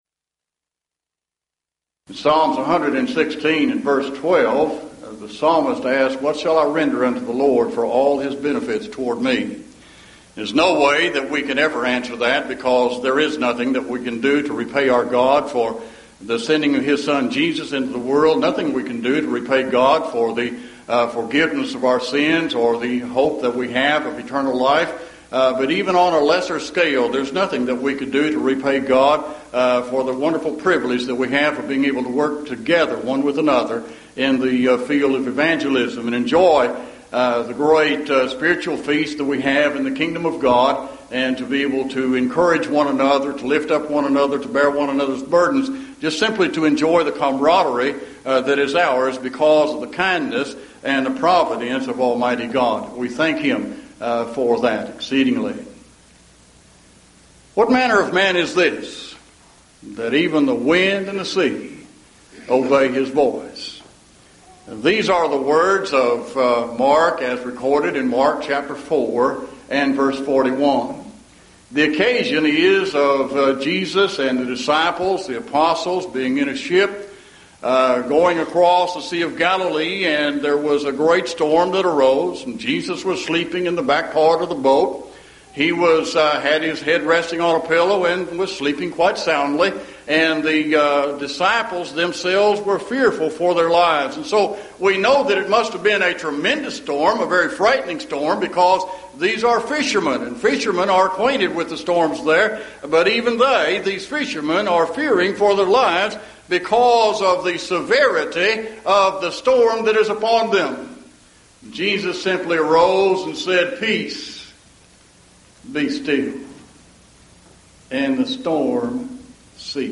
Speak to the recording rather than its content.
Series: Mid-West Lectures Event: 1998 Mid-West Lectures